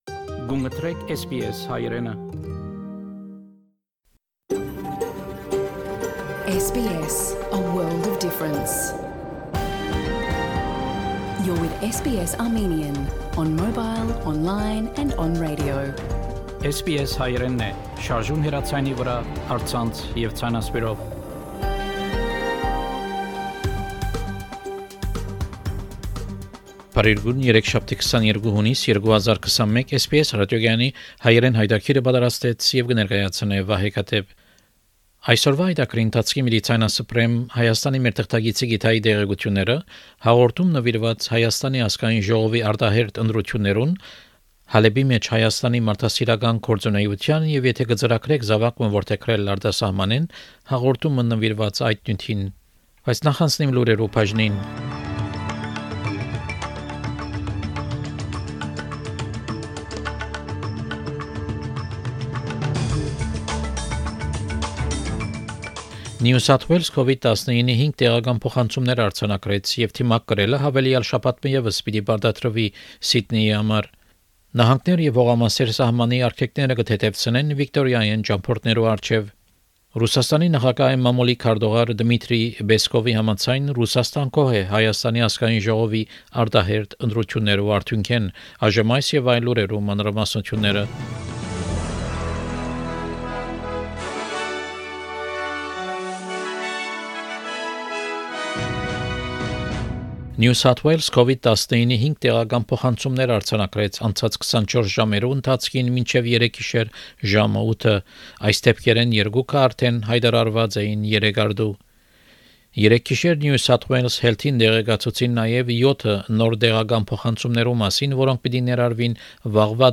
SBS Armenian news bulletin – 22 June 2021
SBS Armenian news bulletin from 22 June 2021 program.